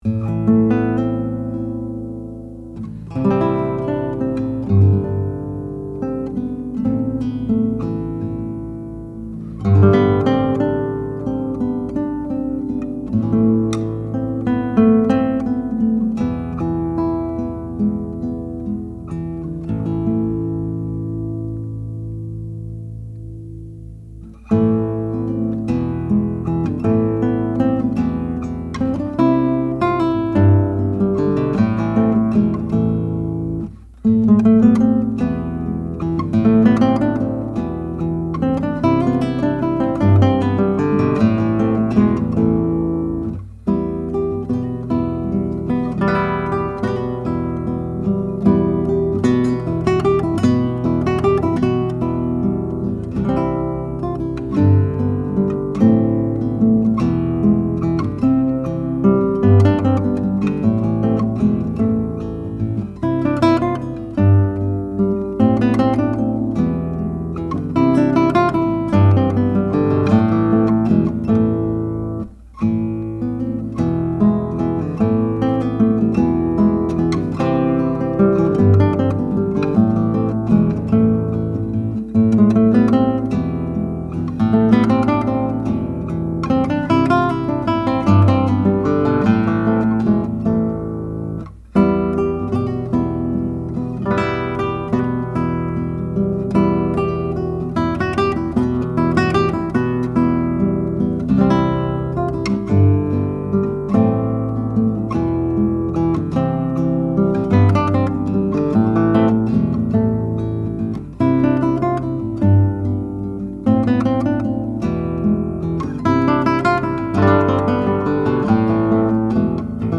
Here are 19 very quick, 1-take MP3 sound files of me playing this guitar, to give you an idea of what to expect. The guitar has amazing sympathetic resonance and sustain, as well as good power and projection, beautiful bass responce, and a very even response across the registers. These MP3 files have no compression, EQ or reverb -- just straight signal, tracked through a Blue Woodpecker ribbon mic, into a Presonus ADL 600 mic preamp, into a Sony PCM D1 flash recorder.
Renaissance, before 1596)